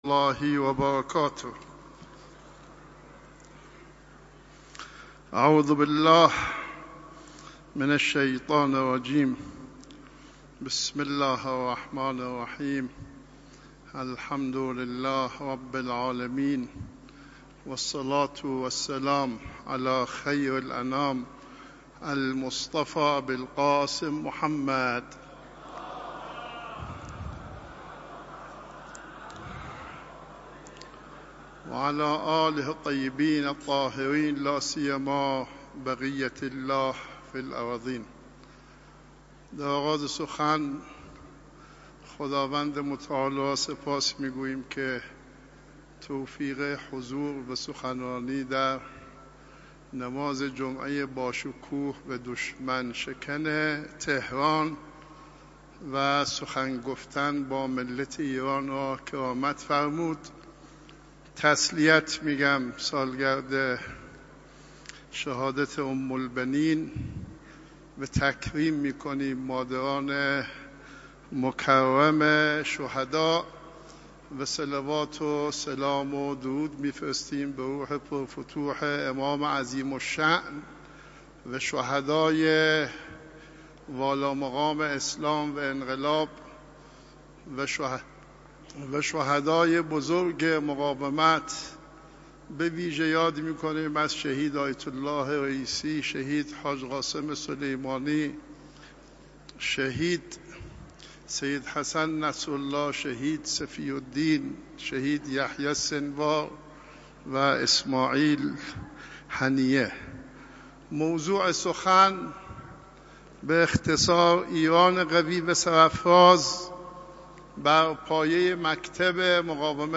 صوت / سخنراني آیت الله کعبی پیش از خطبه نماز جمعه تهران – مرکز علمی فرهنگی امام حسین (ع)